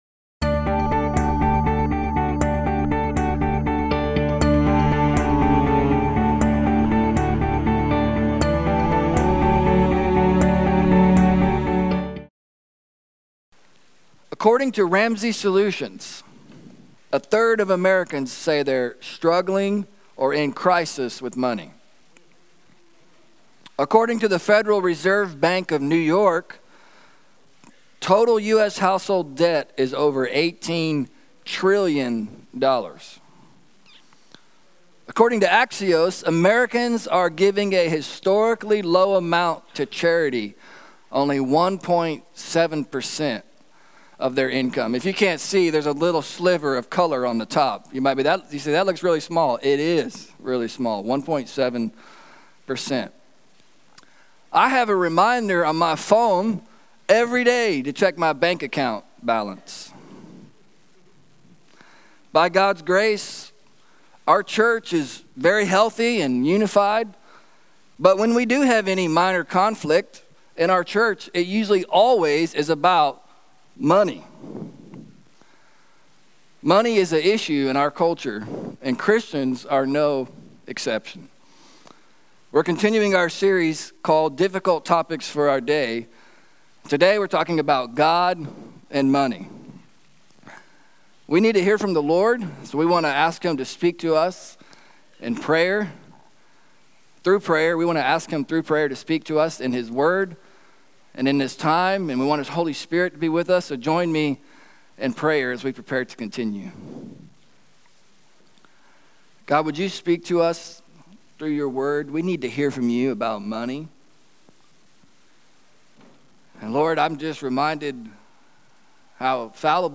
Sermon Podcasts